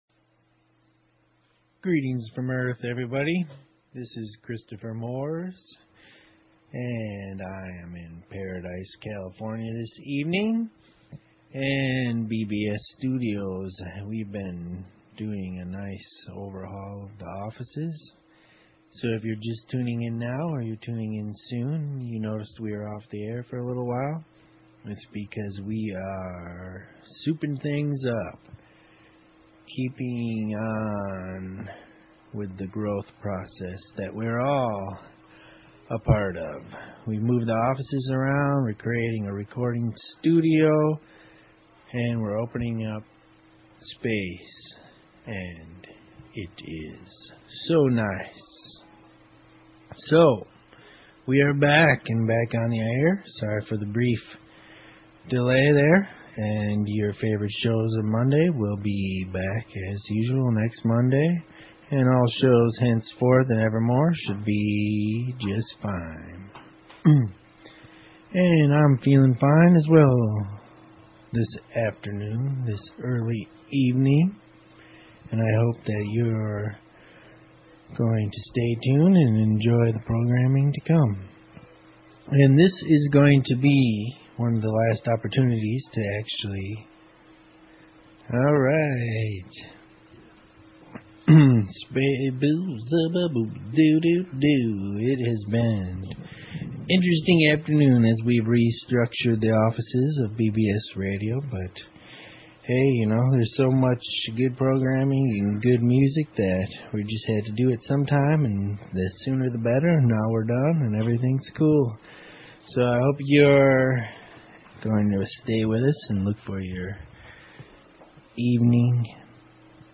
Talk Show Episode, Audio Podcast, TWM and Courtesy of BBS Radio on , show guests , about , categorized as